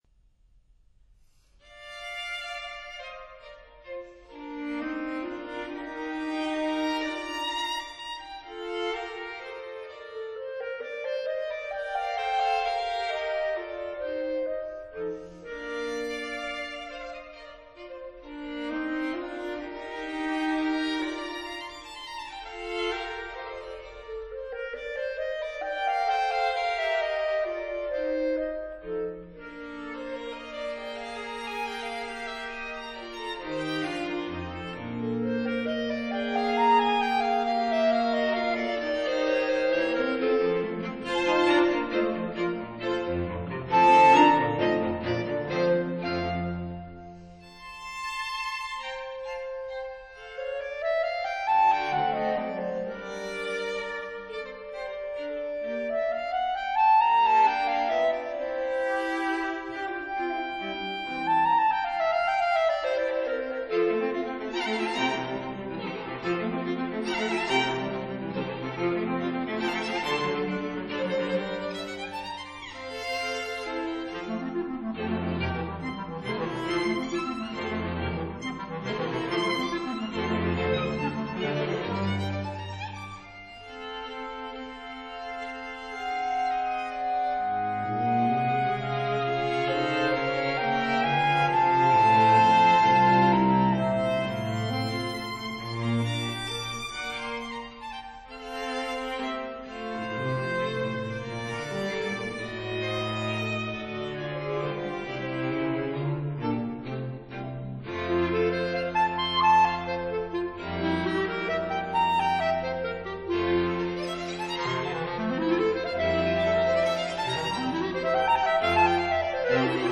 Studio Master